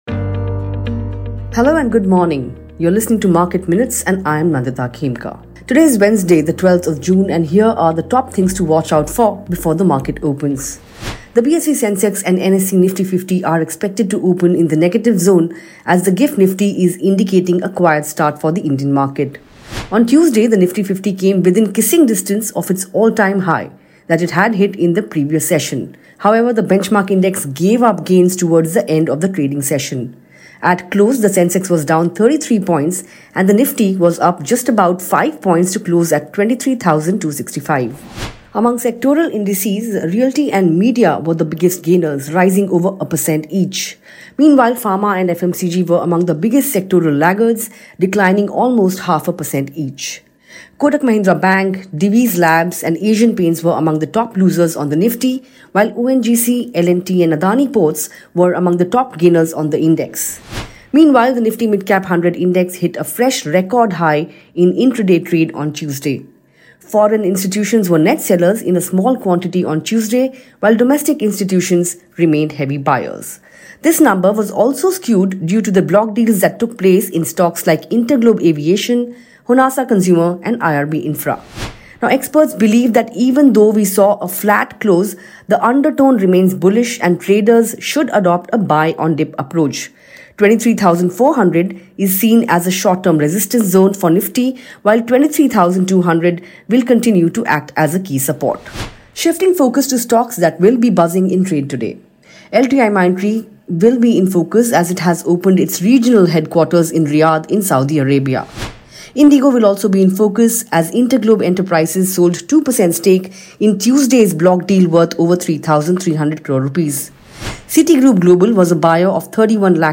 Market Minutes is a morning podcast that puts the spotlight on hot stocks, key data points, and developing trends.